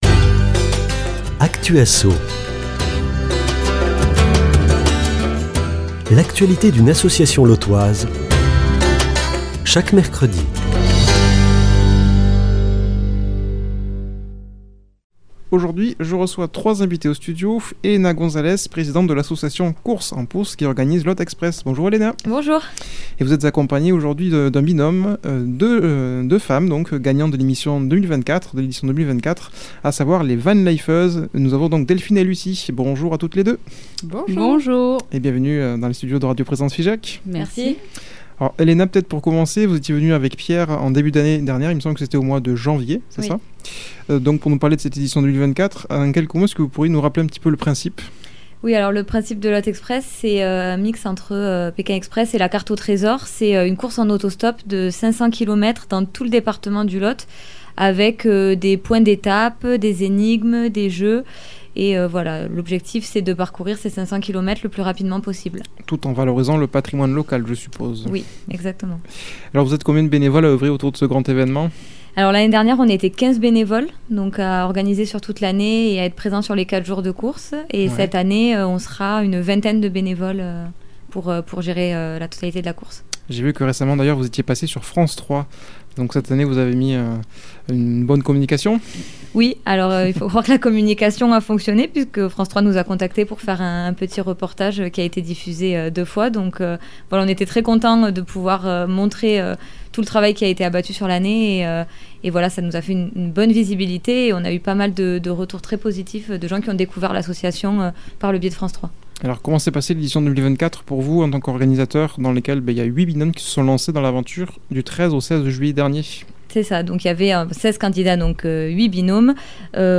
Actu Asso
[ Rediffusion ]